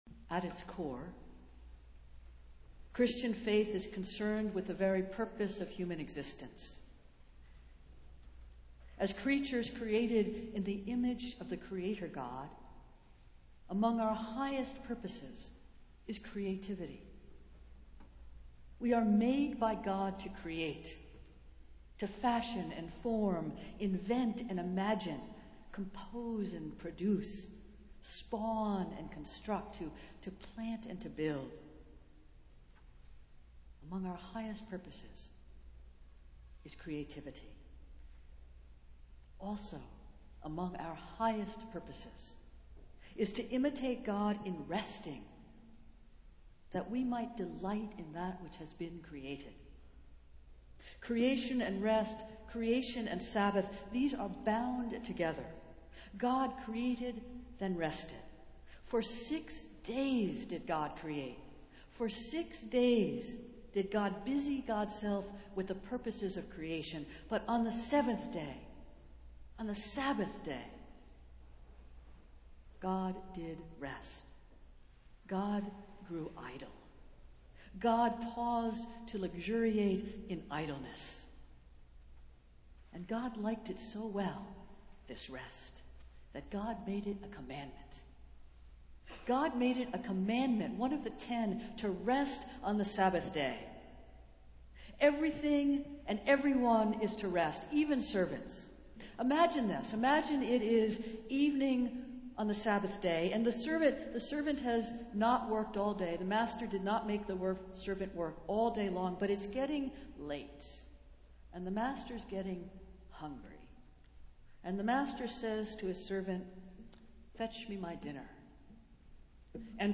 Festival Worship - Third Sunday in Lent